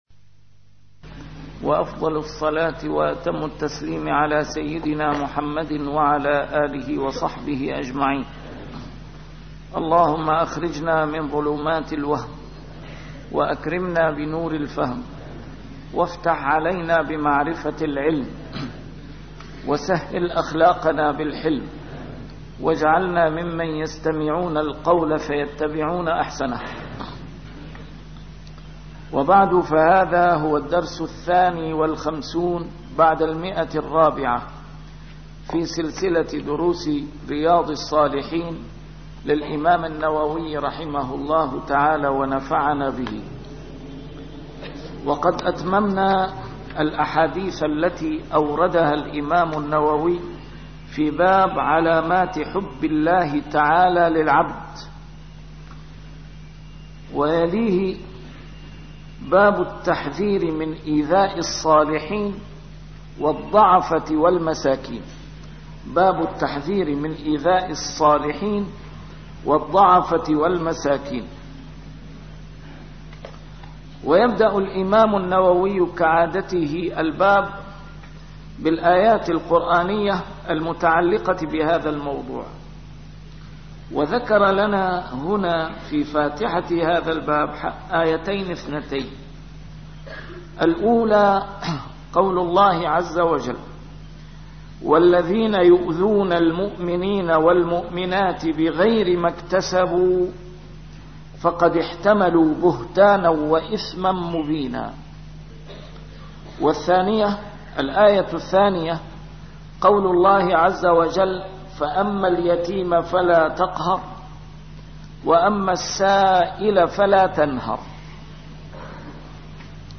A MARTYR SCHOLAR: IMAM MUHAMMAD SAEED RAMADAN AL-BOUTI - الدروس العلمية - شرح كتاب رياض الصالحين - 452- شرح رياض الصالحين: التحذير من إيذاء الصالحين